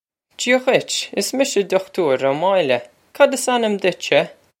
Jee-ah ghwitch. Iss misha Dukh-toor Oh Moyil-eh. Cod iss an-im ditch-sheh?
This is an approximate phonetic pronunciation of the phrase.